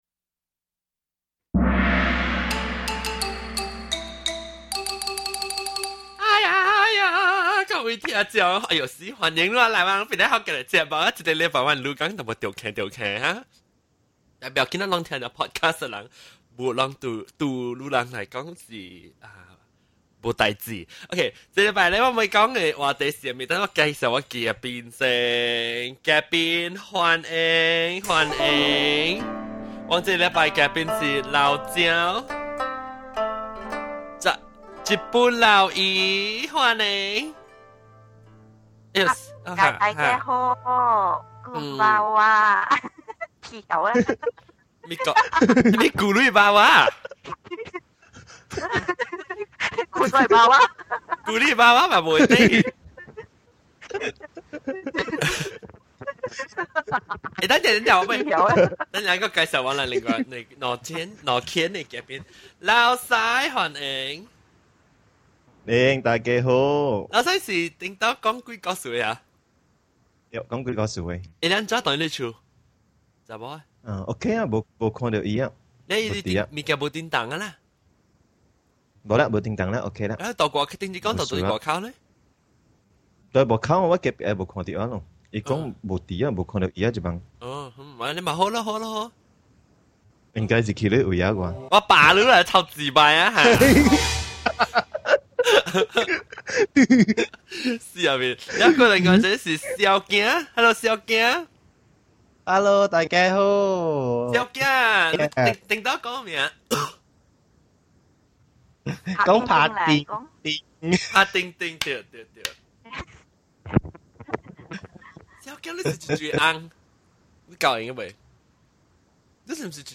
That’s exactly what we’re talking about. As you can imagine, the honest and straight forward discussions is going to cause some big laughs.